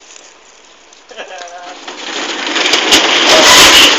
Play, download and share PERSIANA original sound button!!!!
6s_to_10s_bajando_la_persiana_.mp3